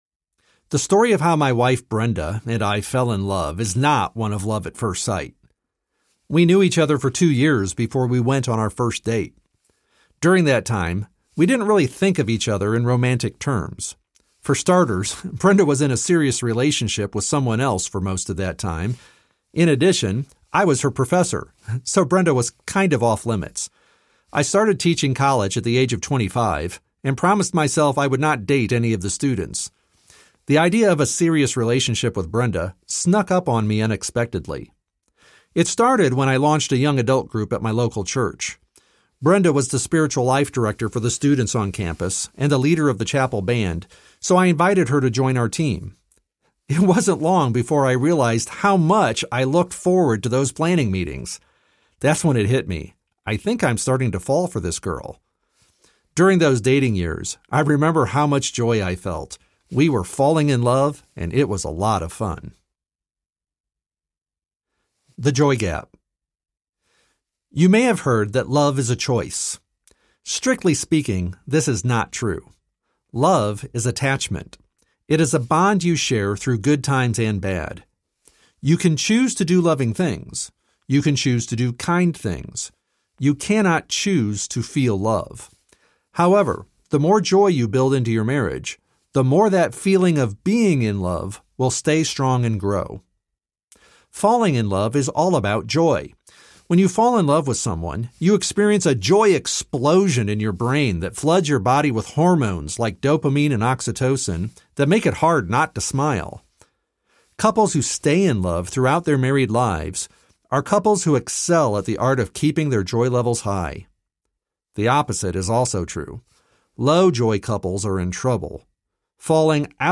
The 4 Habits of Joy-Filled Marriages Audiobook
2.8 Hrs. – Unabridged